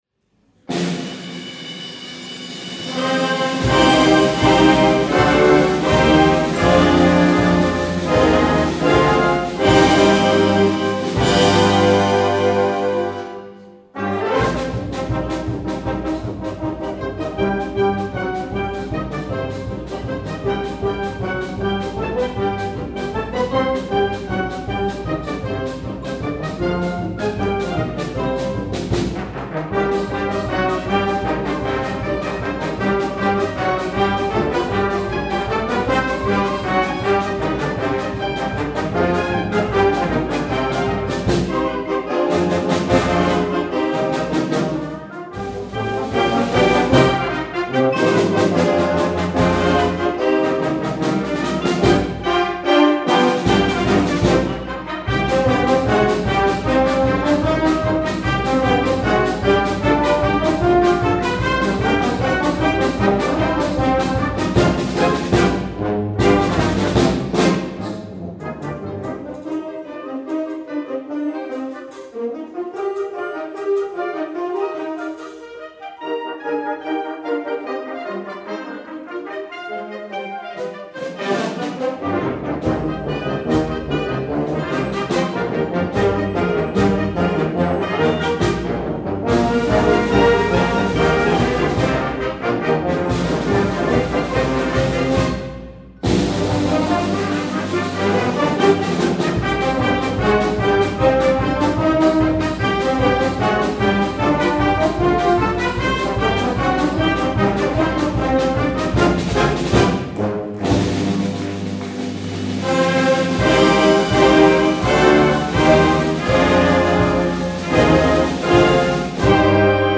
Genre: Blasmusik.